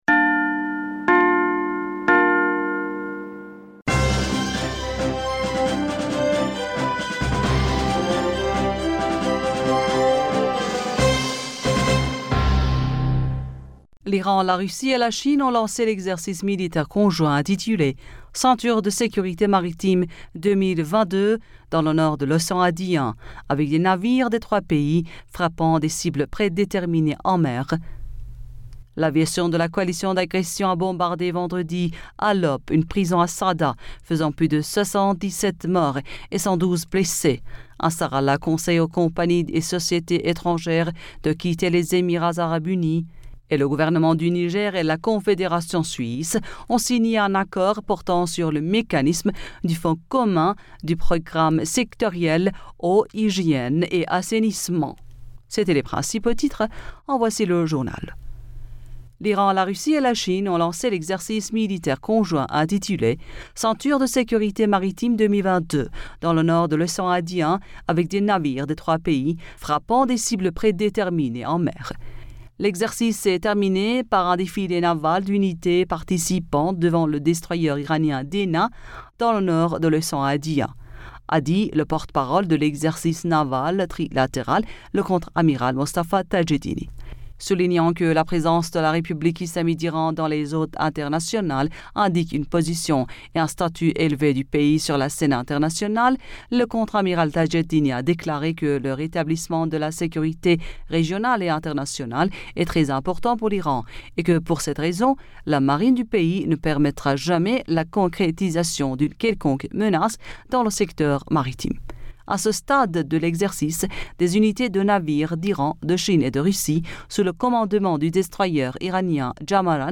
Bulletin d'information Du 22 Janvier 2022